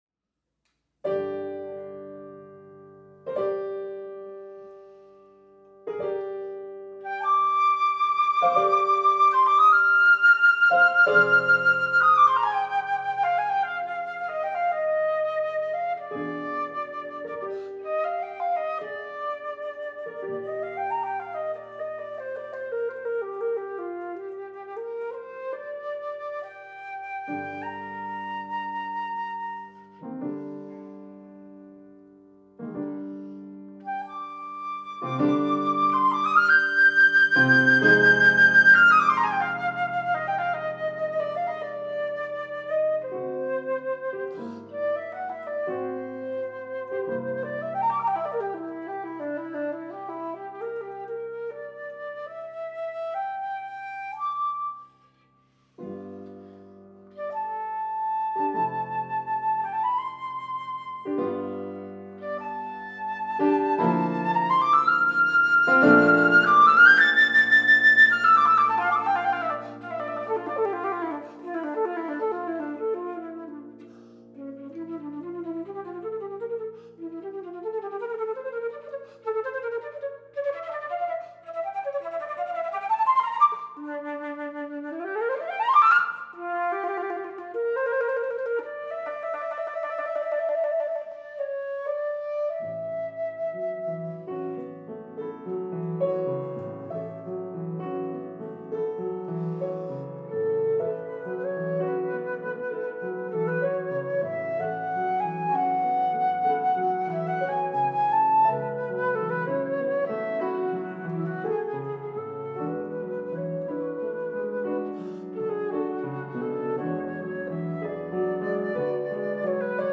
A collection of music recordings on the flute
piano